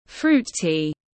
Trà hoa quả tiếng anh gọi là fruit tea, phiên âm tiếng anh đọc là /fruːt tiː/
Fruit tea /fruːt tiː/